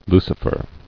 [Lu·ci·fer]